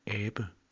Ääntäminen
IPA: [ˈæːb̥ə]